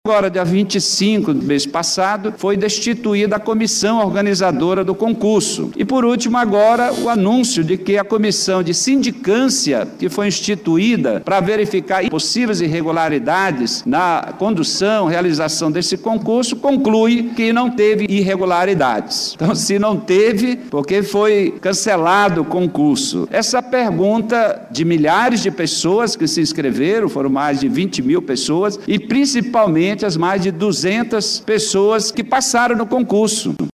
O vereador Zé Ricardo, do PT, também se pronunciou sobre o tema e cobrou um posicionamento da Mesa Diretora da CMM.